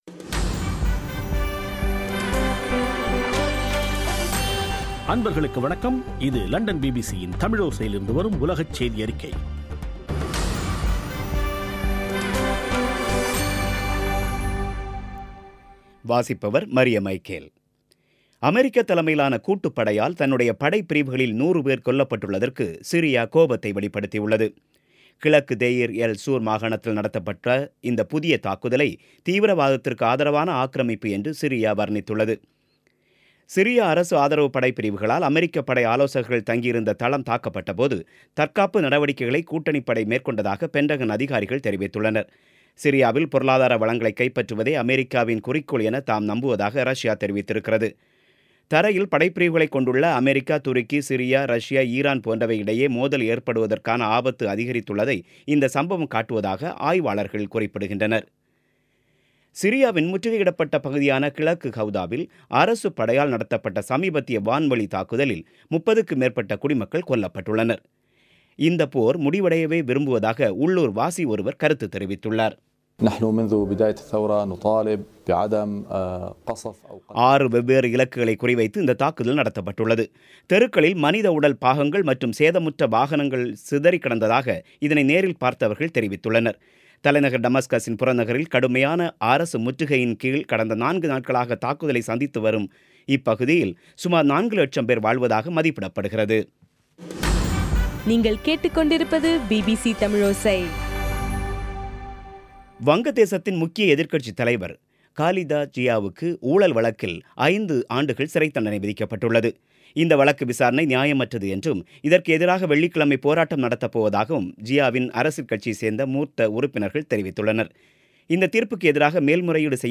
பிபிசி தமிழோசை செய்தியறிக்கை (08/02/2018)